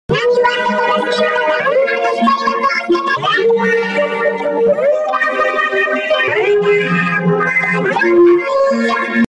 fudud in capcut electronic sounds sound effects free download